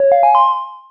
Resources - auditory.vim - vim interface sounds
auto_complete.wav